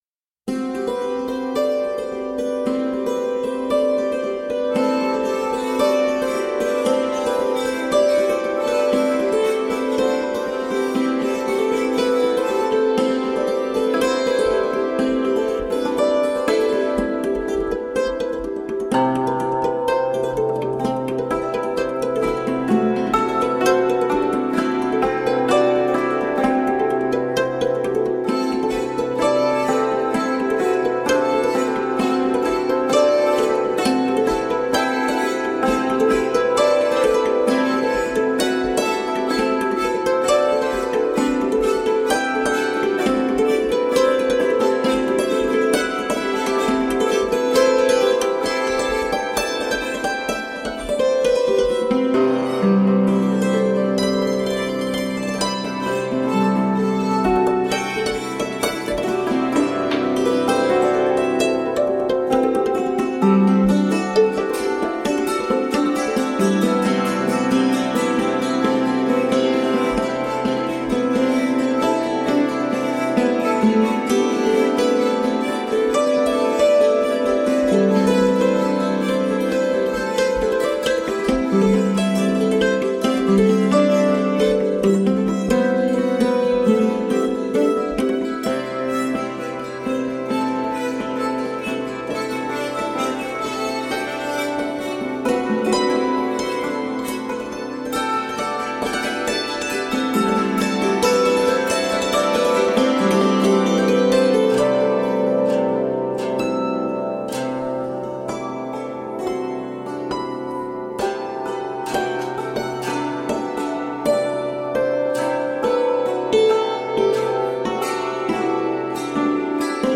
Innovative hammered dulcimer.